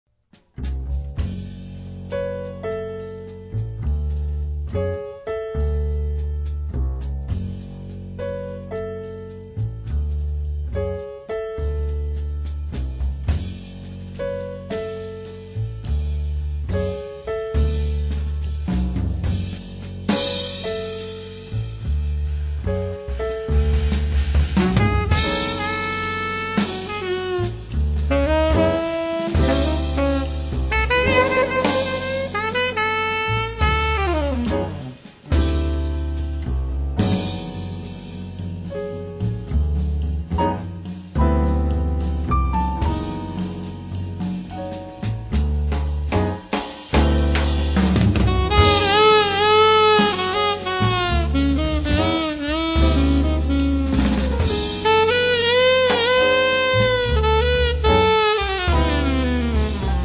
batteria
sax tenore
sax alto e soprano
piano
contrabbasso